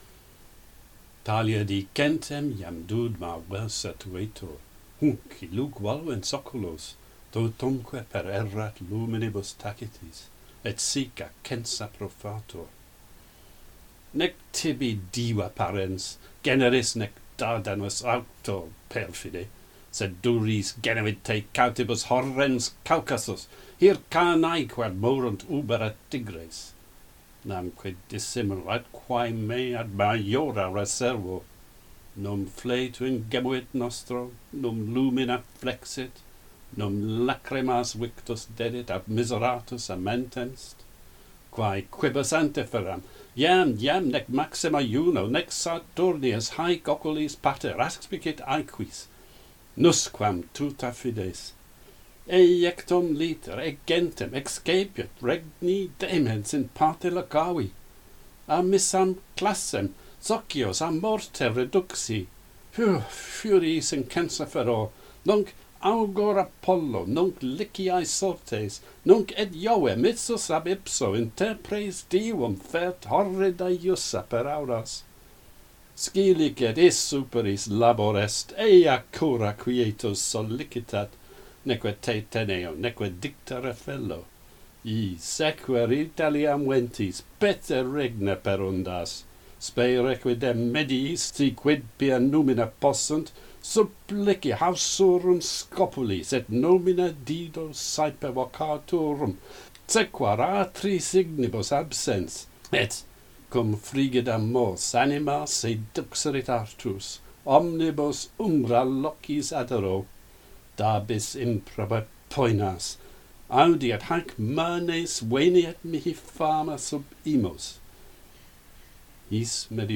- Pantheon Poets | Latin Poetry Recited and Translated